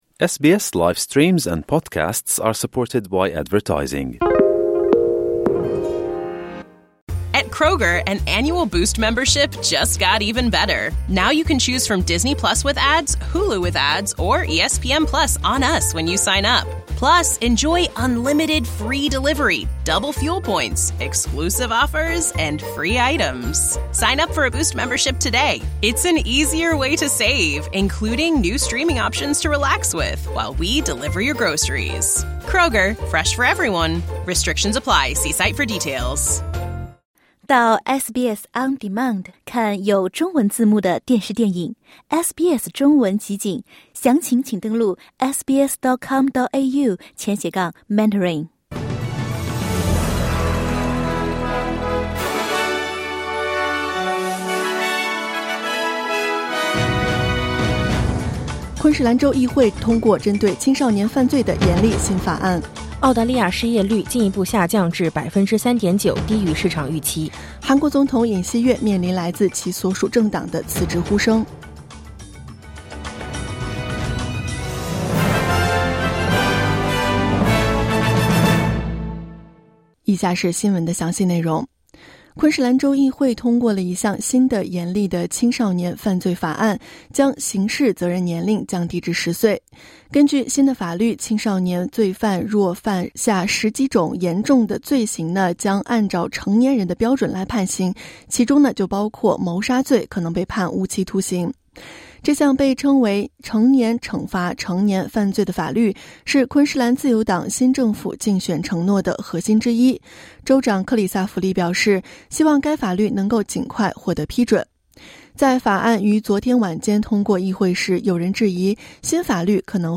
SBS早新闻（2024年12月13日）